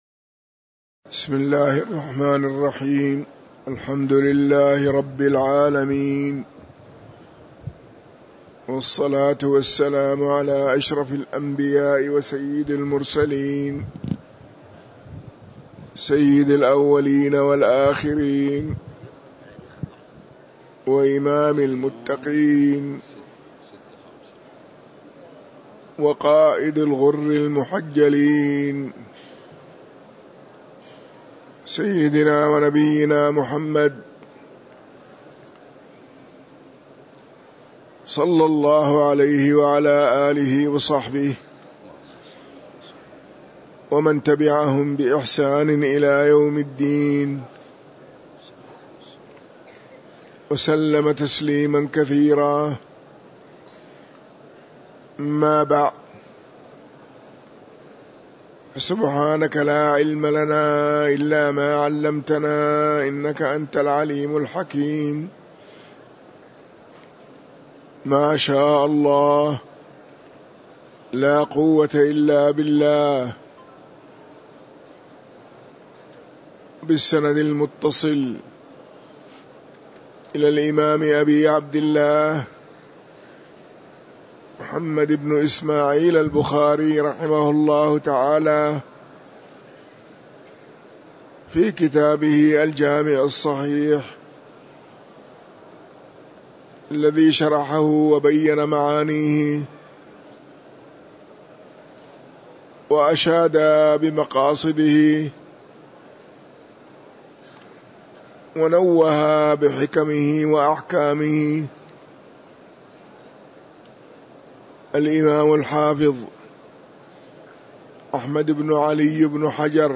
تاريخ النشر ٦ جمادى الأولى ١٤٤٤ هـ المكان: المسجد النبوي الشيخ